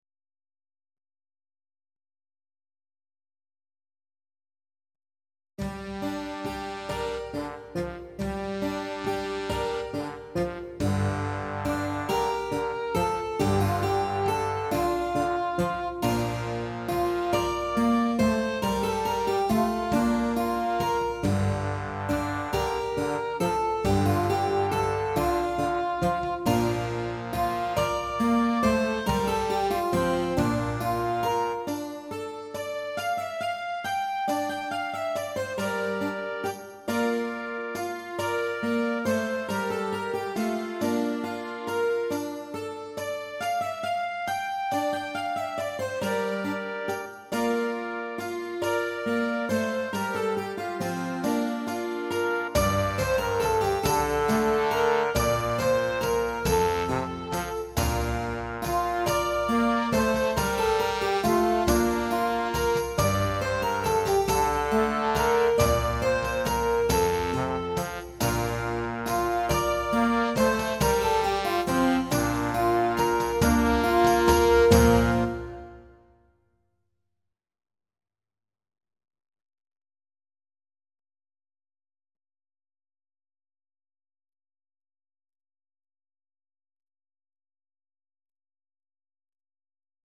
31 Estampie (Backing Track)